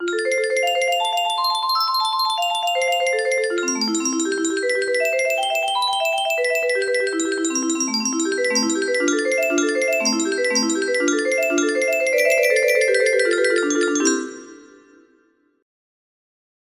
testing music box melody